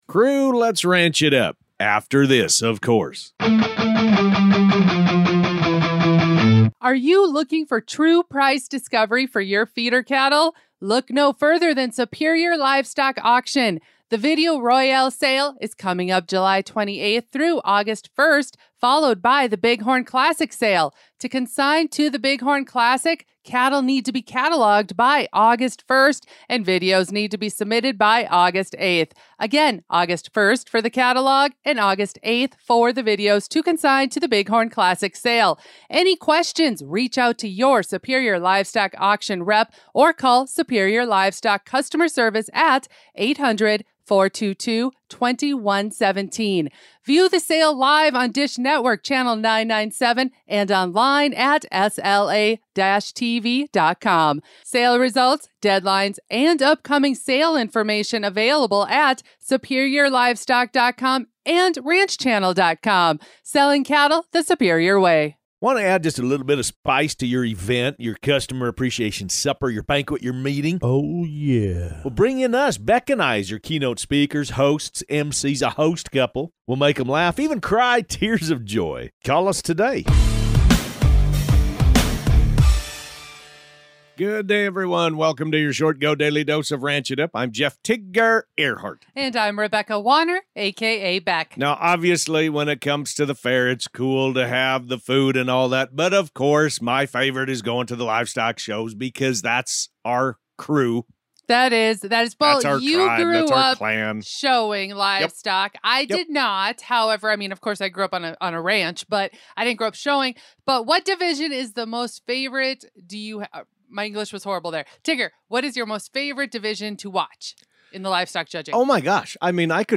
They're serving up the most important ranch-related headlines, from new flavor drops and condiment controversies to the best pairings and fan favorites. Expect insightful (and hilarious) commentary, listener shout-outs, and everything you need to stay in the loop on all things ranch.